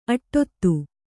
♪ aṭṭottu